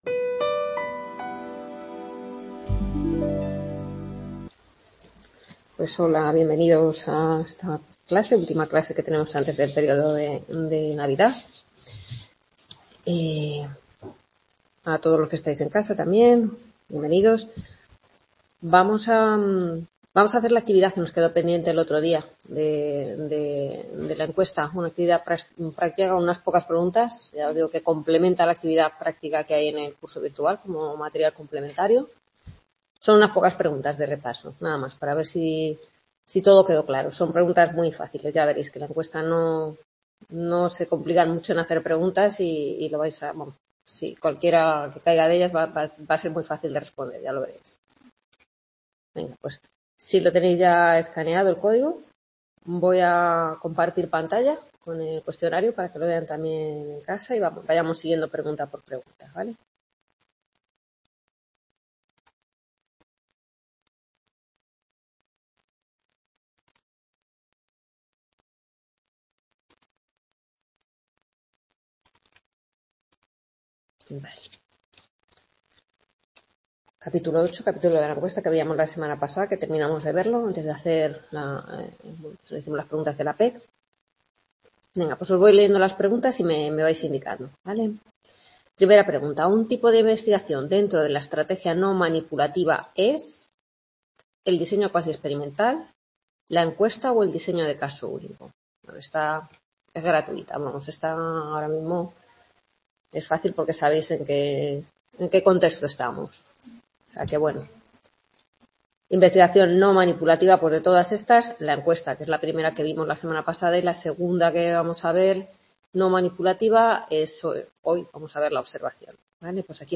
Grabación de la undécima tutoría de la asignatura Fundamentos de investigación del Grado en Psicología impartida en el C.A. Rivas (UNED, Madrid). Corresponde a la realización de un cuestionario de repaso del capítulo 8, Encuesta, y la explicación del tema 9, La Observación.